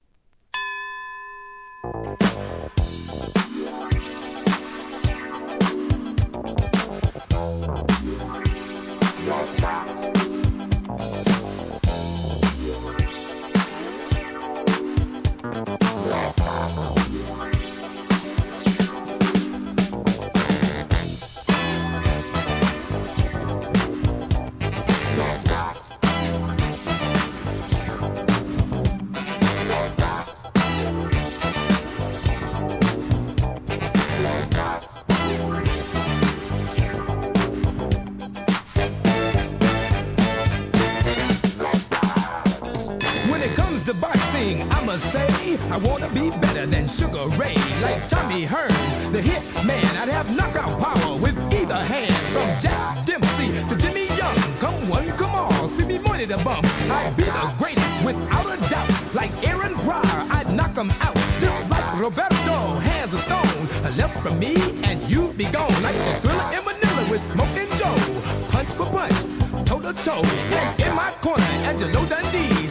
1983年、ヴォコーダ入の怒FUNKで得意のスポーツネタ! OLD SCHOOL HIP HOP!!